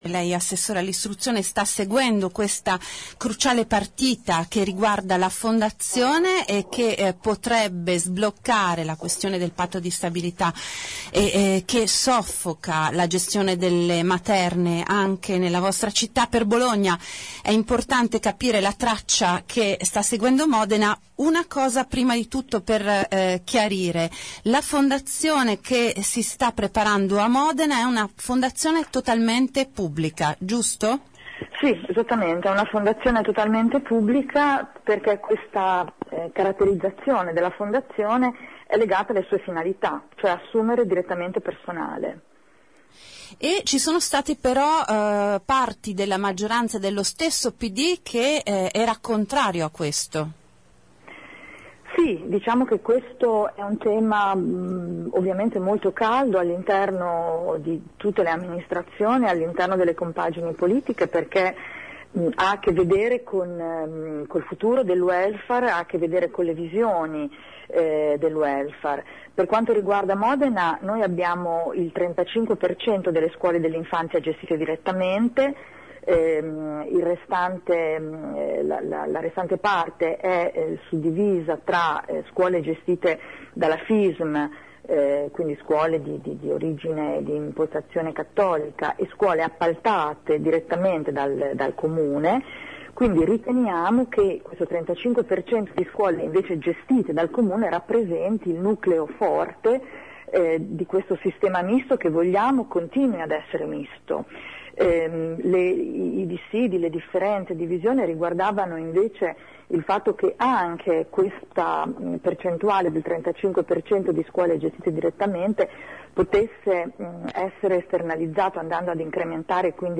Nell’intervista